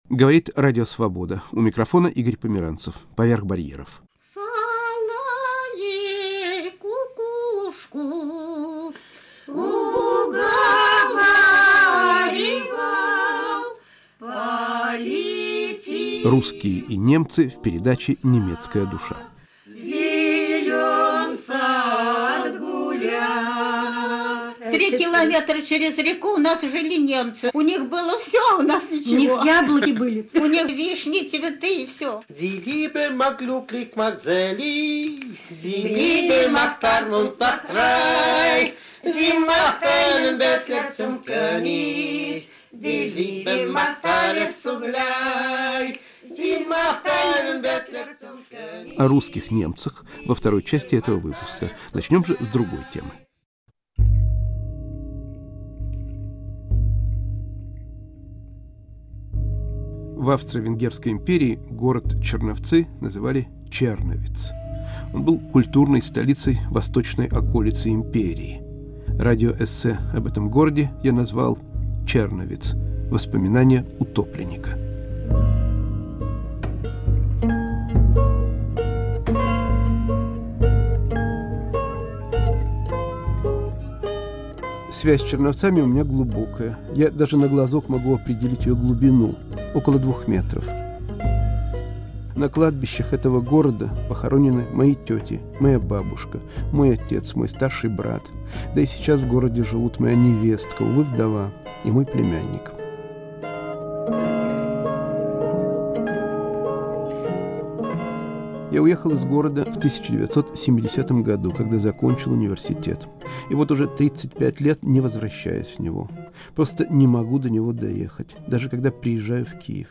Радиоэссе из пятничного выпуска радиопрограммы "Поверх барьеров".